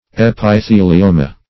Search Result for " epithelioma" : Wordnet 3.0 NOUN (1) 1. a malignant tumor of the epithelial tissue ; The Collaborative International Dictionary of English v.0.48: Epithelioma \Ep`i*the`li*o"ma\, n. [NL.
epithelioma.mp3